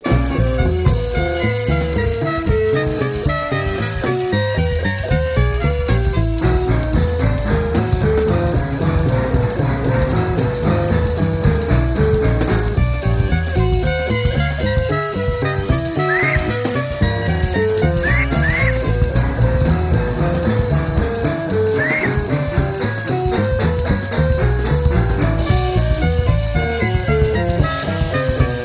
jazz - math